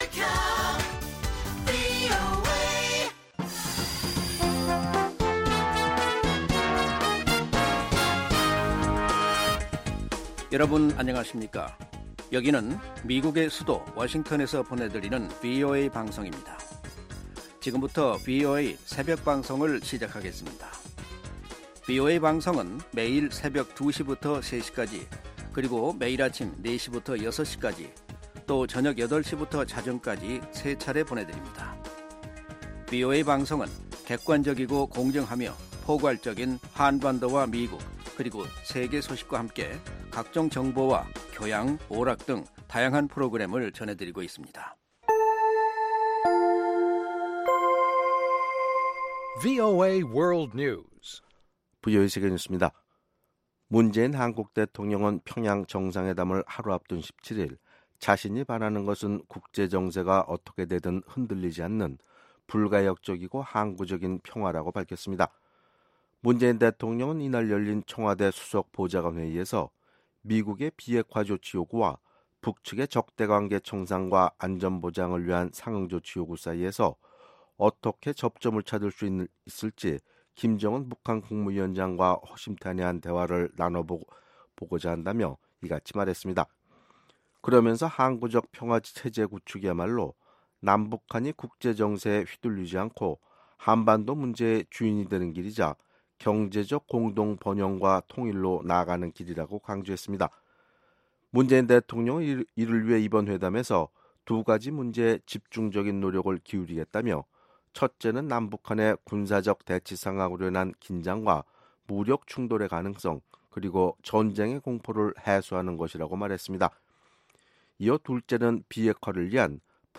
VOA 한국어 '출발 뉴스 쇼', 2018년 9월 18일 방송입니다. 문재인 한국 대통령의 18일 평양 방문으로 열리는 3차 남북정상회담의 중요 의제 가운데 하나는 비핵화를 위한 미-북 대화 중재와 촉진이라고 한국 청와대 대통령 비서실장이 말했습니다. 미 국무부는 문재인 한국 대통령의 평양행에 주요 대기업 총수들이 동행하는데 대해 대북 제재 이행 의무를 상기시켰습니다.